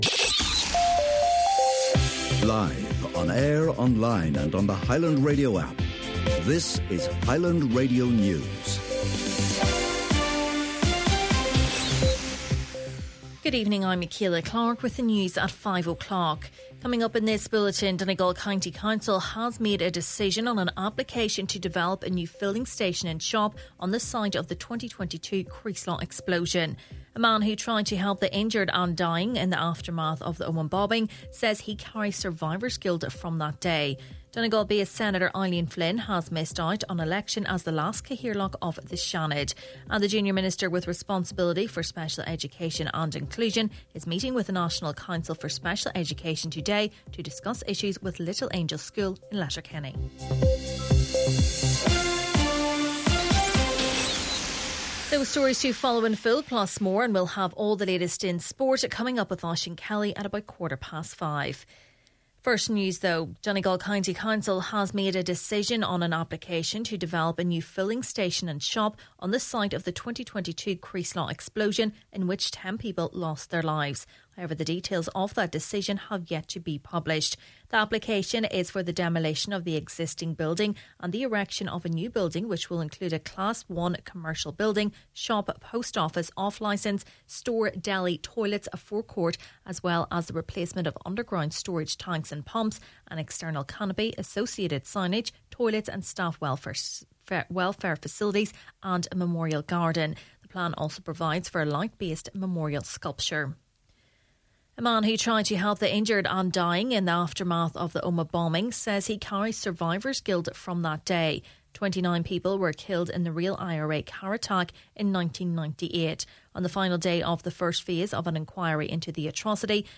Main Evening News, Sport and Obituaries – Wednesday, February 19th